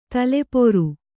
♪ tale poru